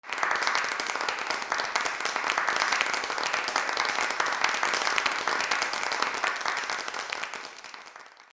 / G｜音を出すもの / G-50 その他 手をたたく　体
拍手 3 数人
『パチパチ』